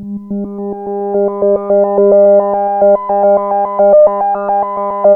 JUP 8 G4 11.wav